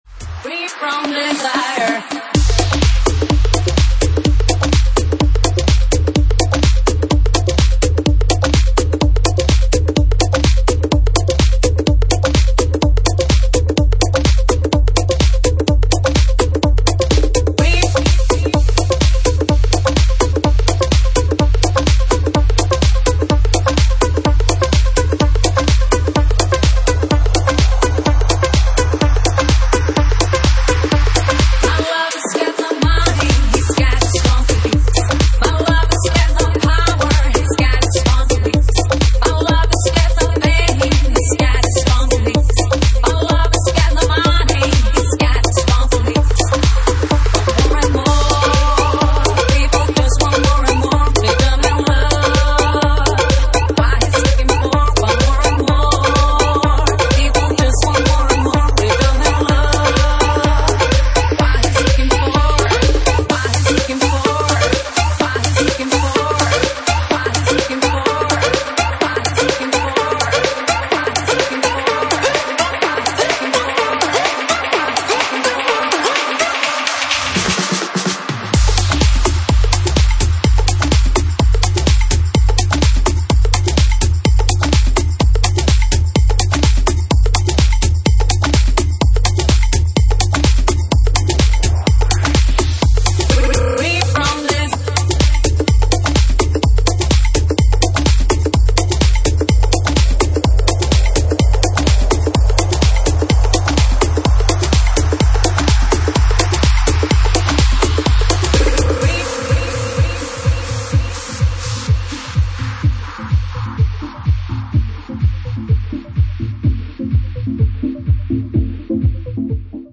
Genre:Jacking House
Jacking House at 127 bpm